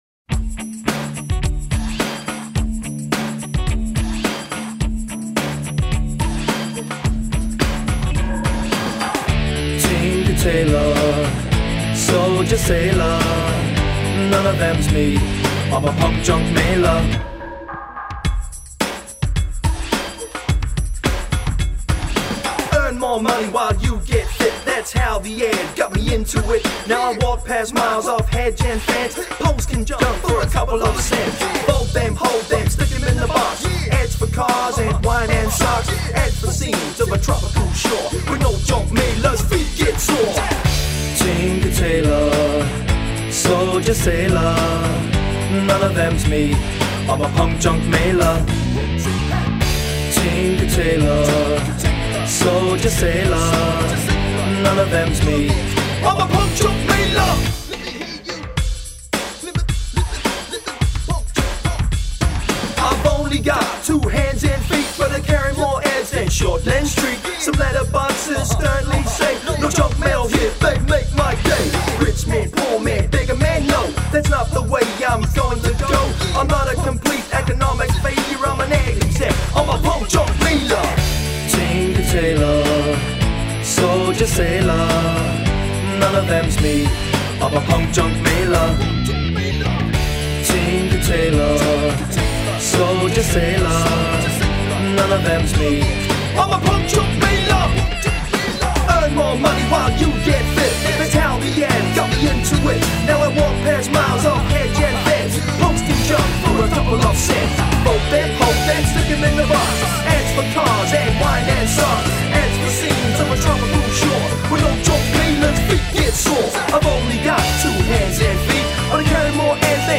01-4-1 Punk Junk Mailer - song (2:30)